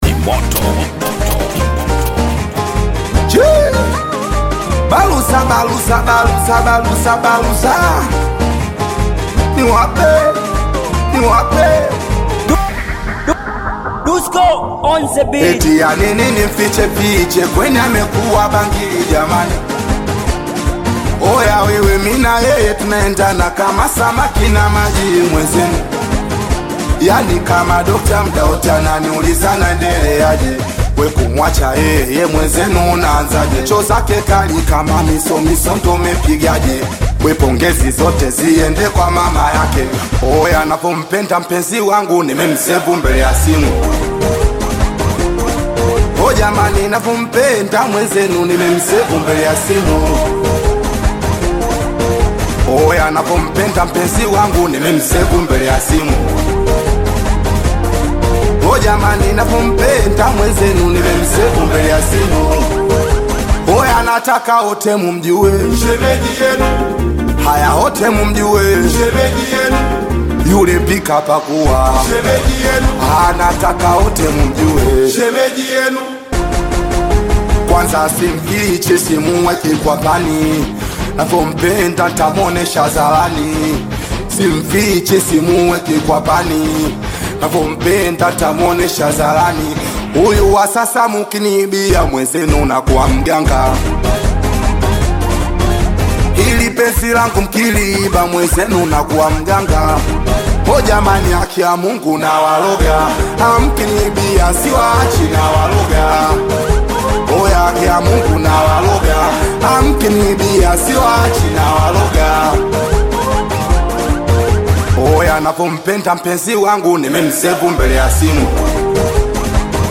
Singeli music track
Tanzanian Bongo Flava singeli
Singeli song